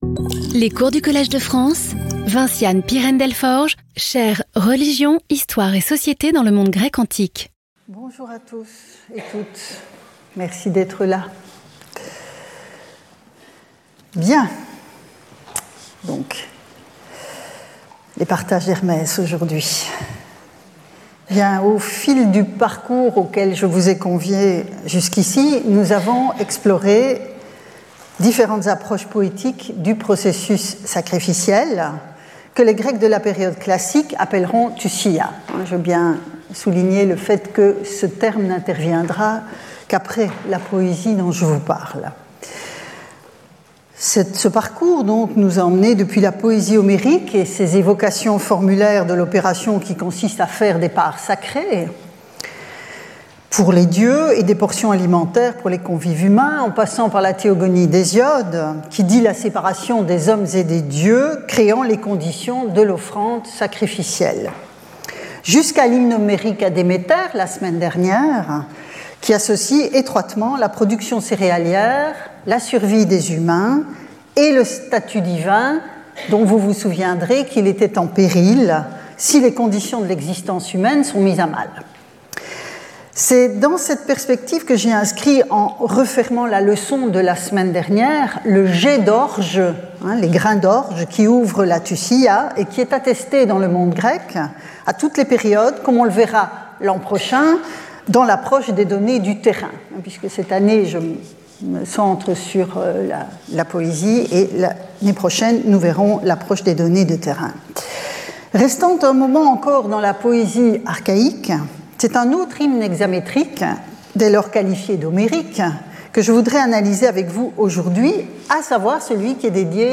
Après l’Hymne homérique à Déméter du cours précédent, c’est celui d’Hermès qui fait l’objet de la présente leçon. Comme les autres œuvres du même type, cet hymne explore la timē du dieu, les honneurs qui doivent lui revenir.